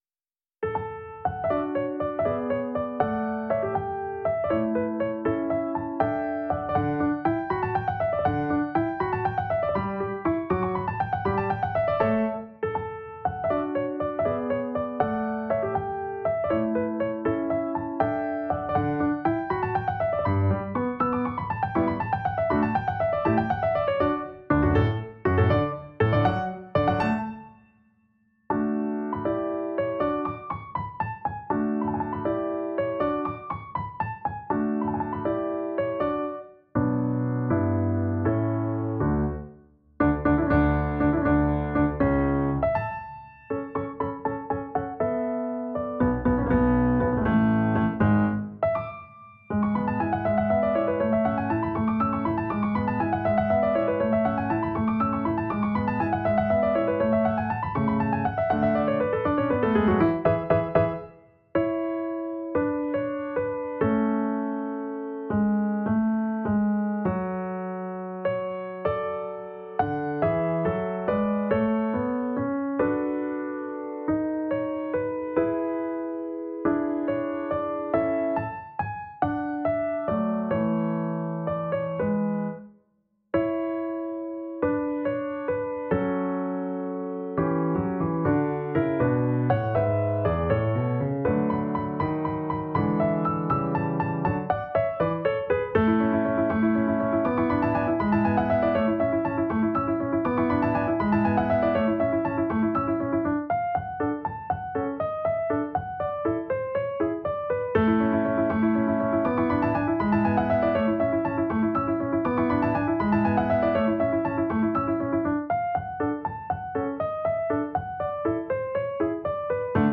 réorchestrations uniques